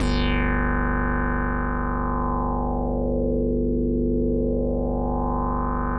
G1_raspy_synth.wav